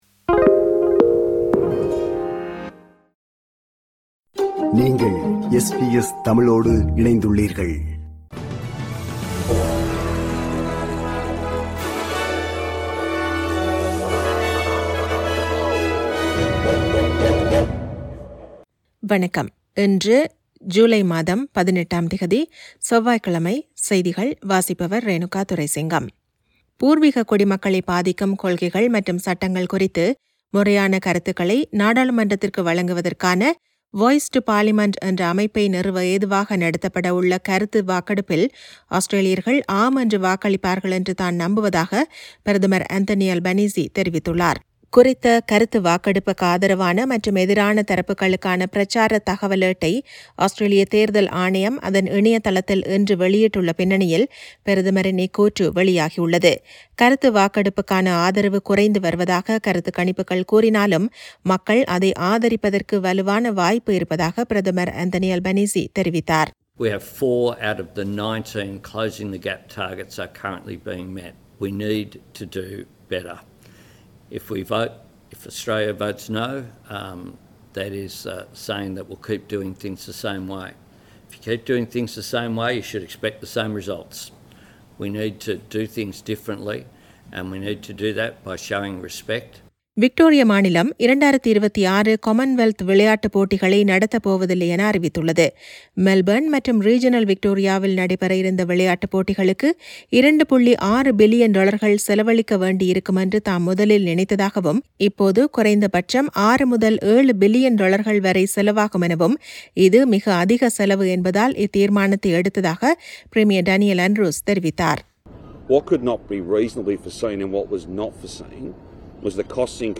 SBS தமிழ் ஒலிபரப்பின் இன்றைய (செவ்வாய்க்கிழமை 18/07/2023) செய்திகள்.